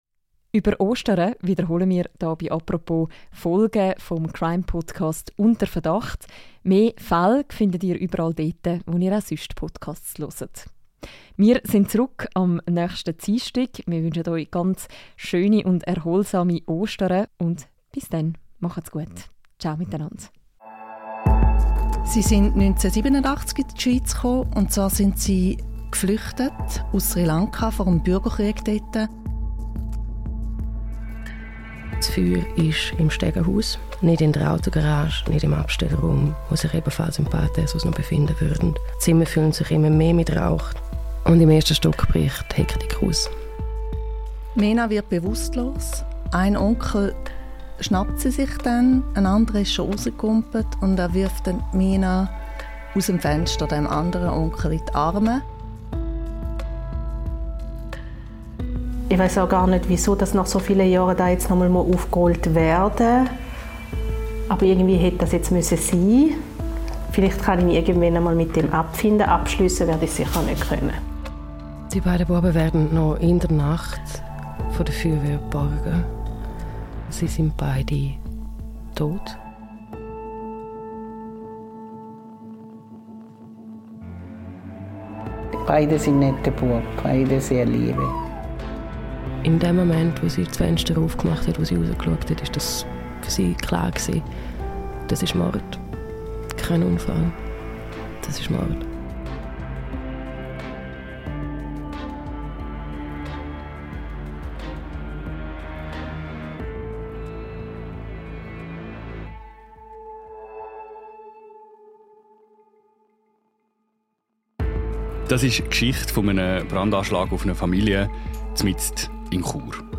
Was sie dabei herausgefunden haben, erzählen sie in einer neuen Staffel des Podcasts «Unter Verdacht».